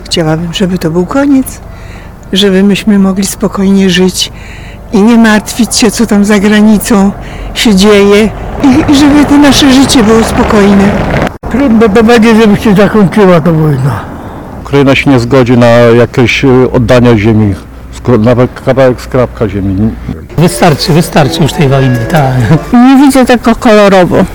Zapytaliśmy mieszkańców Tarnowa, czy wierzą w rychłe zakończenie wojny.